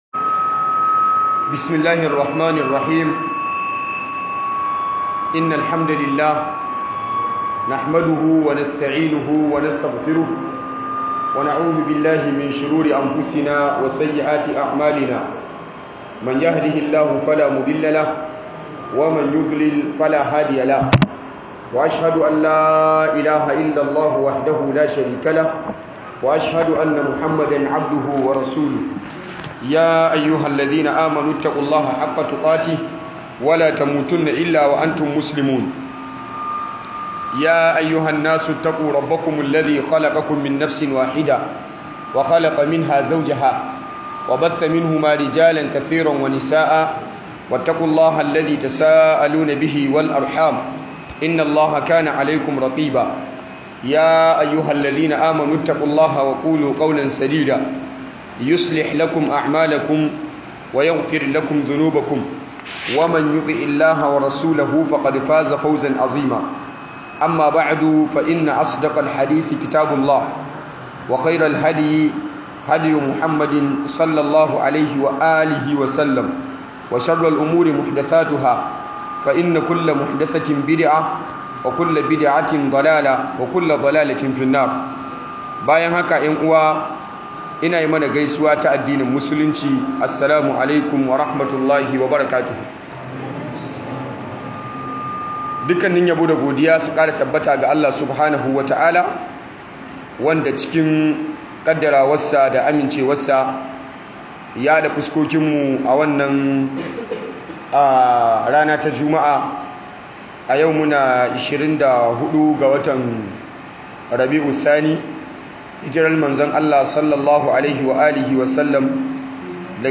Babu Musulunci Ga Wanda Bashi Da Kyakykyawar Aqidah - MUHADARORI